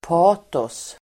Ladda ner uttalet
patos substantiv, passion Uttal: [p'a:tås] Böjningar: patoset Synonymer: glöd Definition: stark eller högstämd känsla (devotion, fervour, also pathos) Exempel: hennes politiska patos (her political passion)